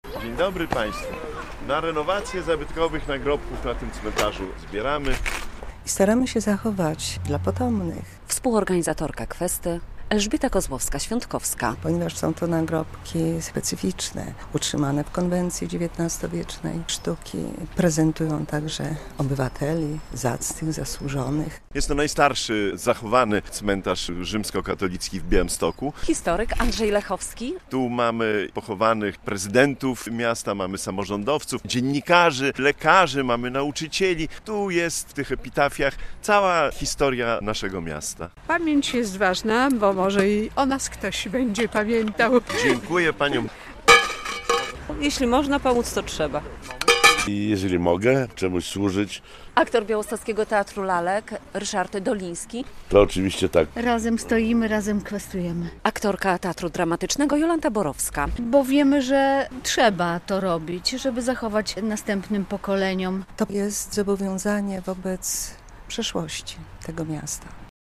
Kwesta na cmentarzu Farnym w Białymstoku - relacja